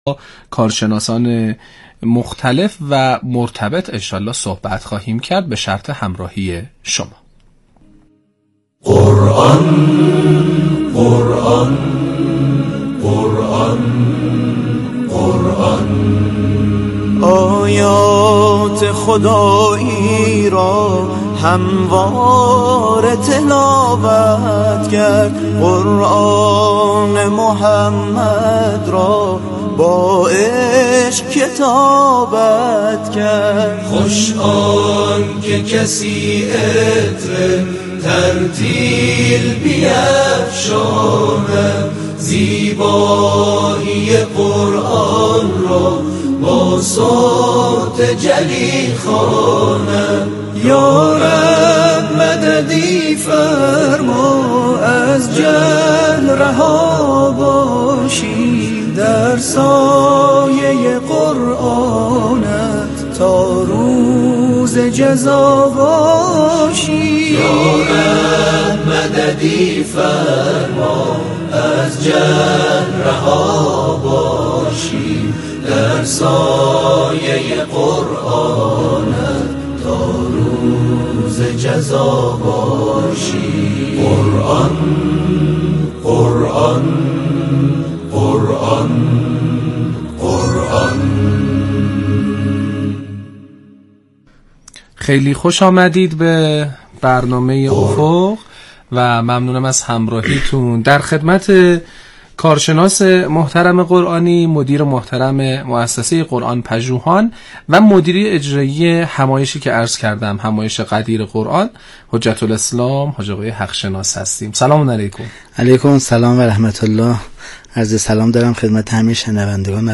برنامه رادیویی افق امروز طی بخش نخست با تشریح جزئیات همایش دو روزه غدیریه قرآنی بانوان و در بخش دوم با ارائه دو برنامه مهم اداره‌کل آموزش معاونت قرآن و عترت در آینده نزدیک شامل طراحی و ایجاد دیپلم مهارتی در رشته حفظ قرآن و آموزش‌های تبلیغی و ترویجی قرآنی به افراد صاحب مهارت و تخصص در حوزه فرهنگ و هنر و رسانه به روی آنتن رفت.